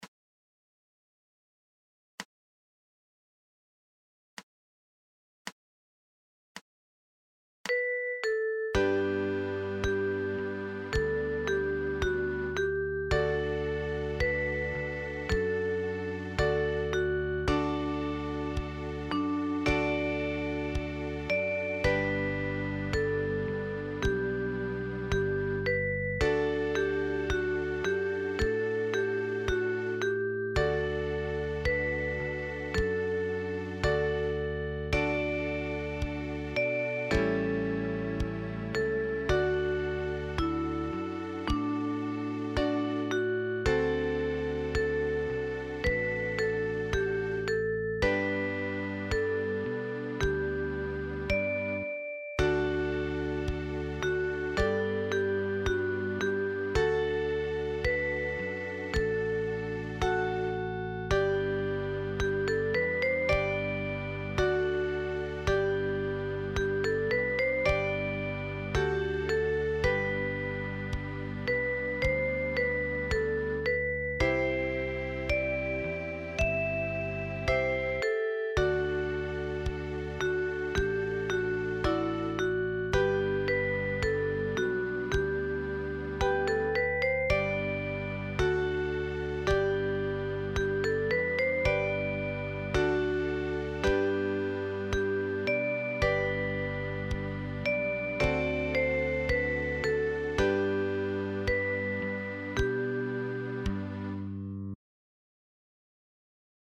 Blockflöte